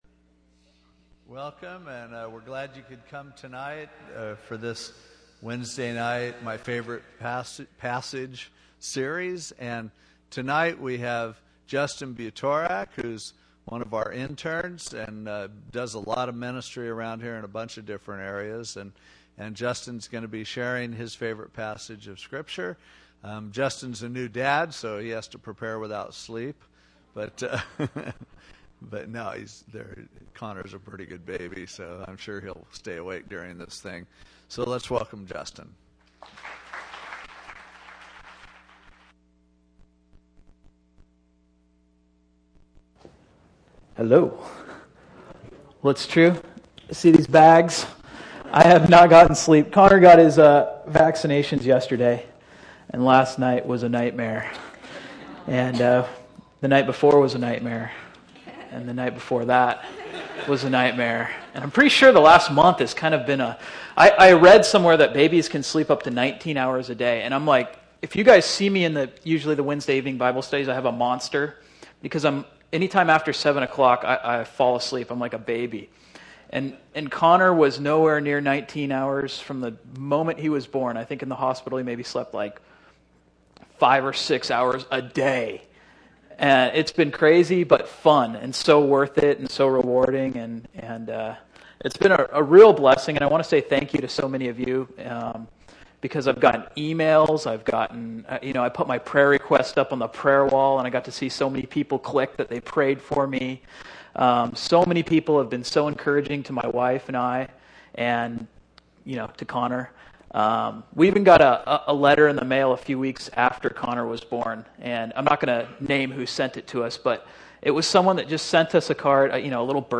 A message from the series "Miscellaneous Messages."